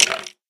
skeletonhurt3.ogg